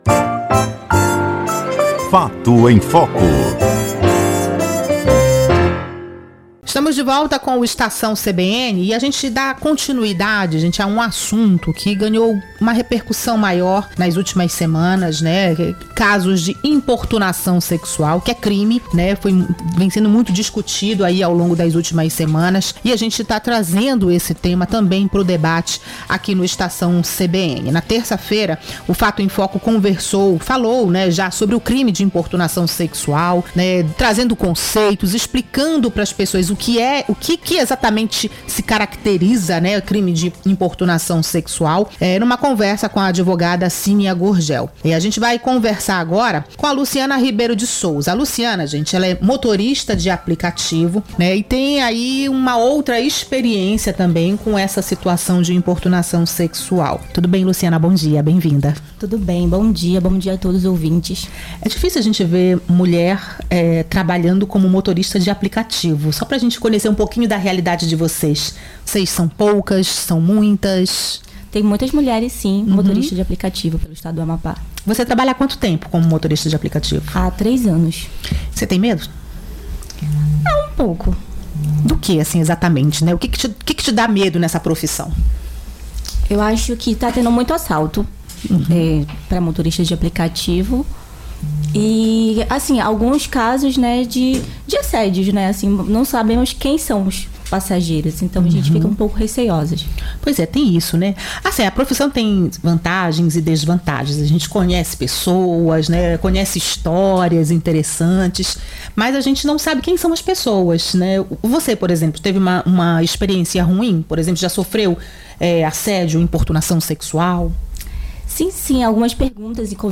Motorista fala sobre trabalhar como motorista de aplicativo